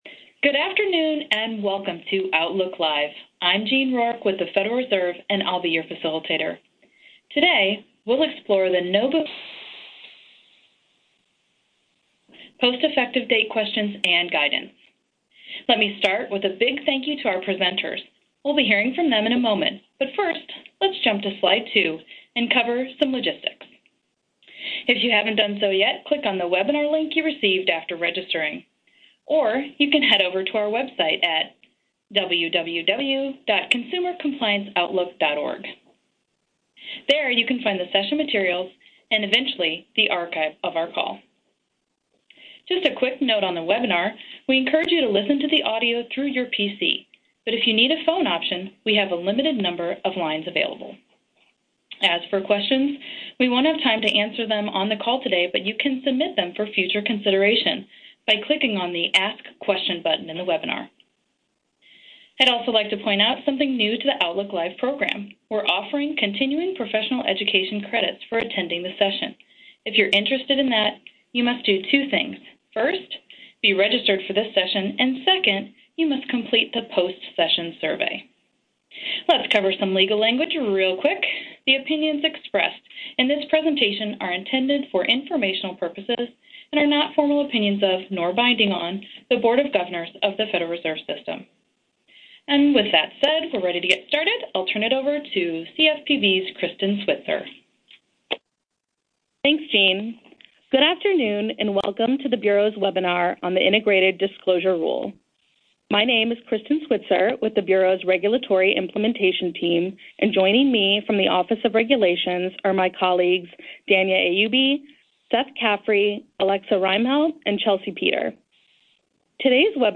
Outlook Live Webinar Archive